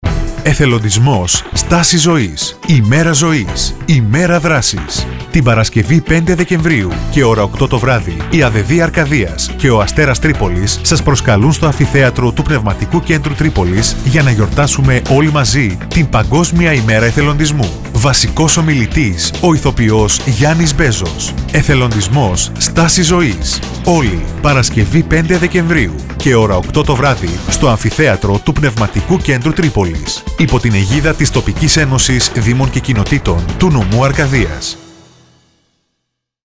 Ενημερωτικό σποτάκι